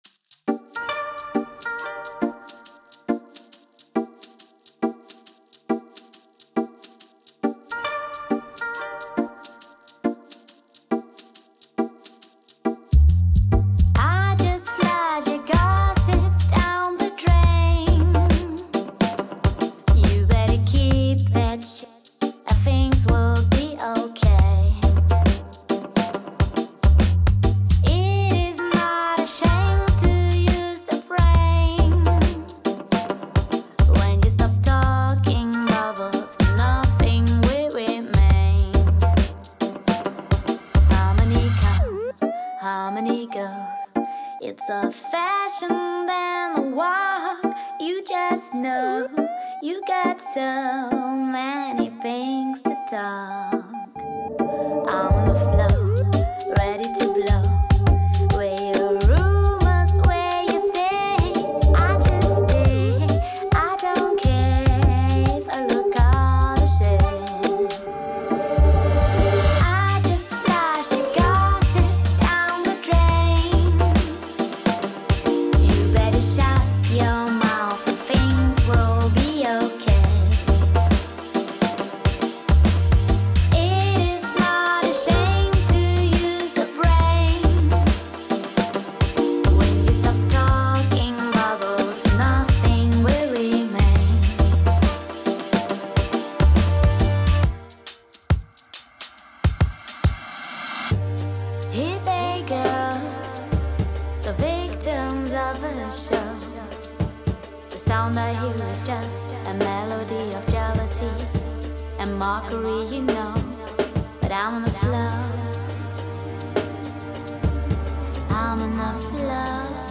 • Genre: Lounge / TripHop